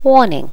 warning.wav